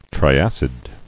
[tri·ac·id]